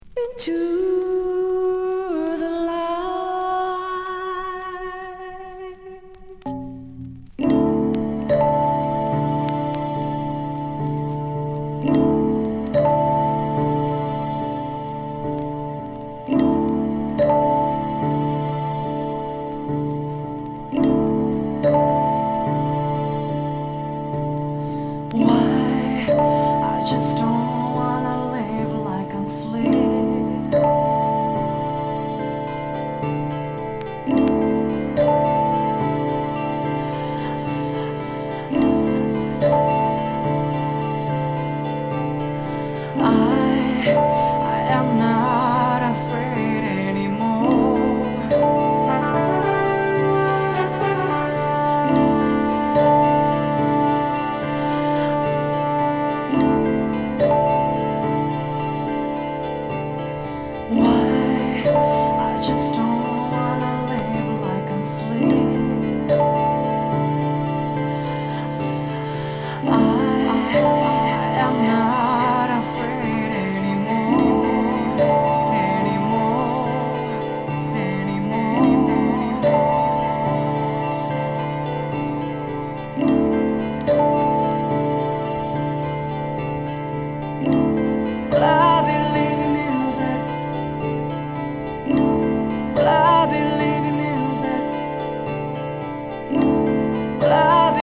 これもまたアコースティック微睡める素晴らしい内容になってます。